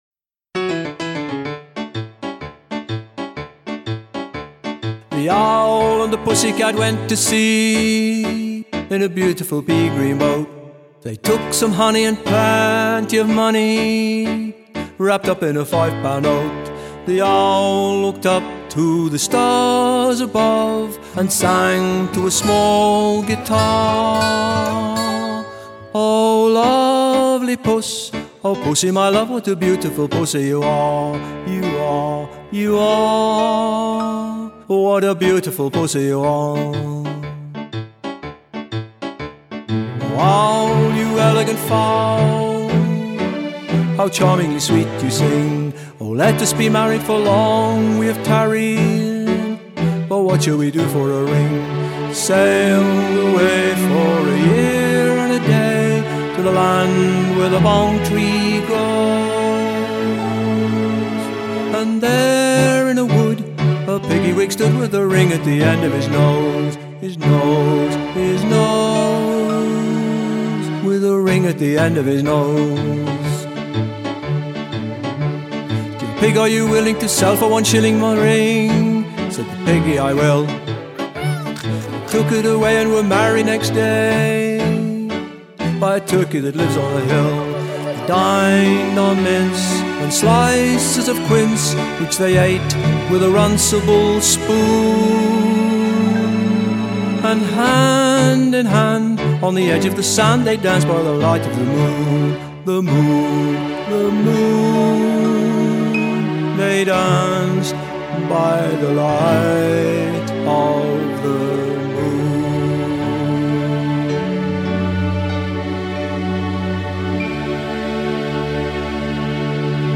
Traditional